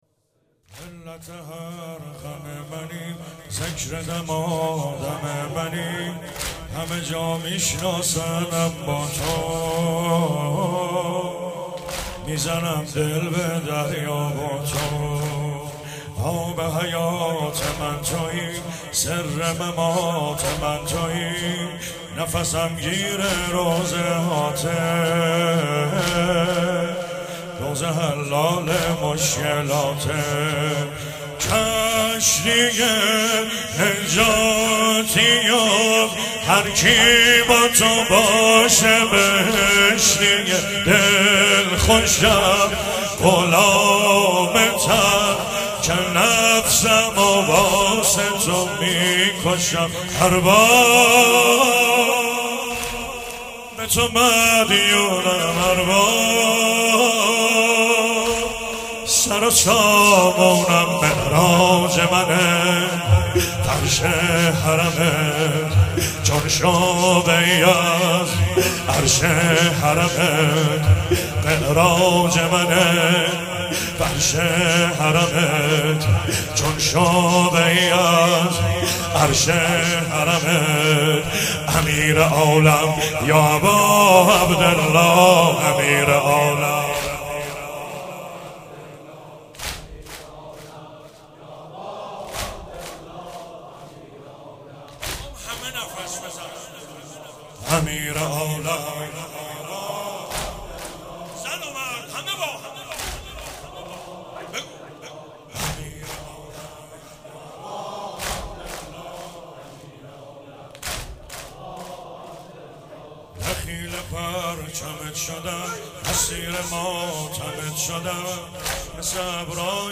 مراسم شب اول محرم الحرام 94
سنگین- علت هر غم منی ذکر دمادم منی